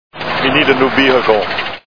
Terminator 3 Movie Sound Bites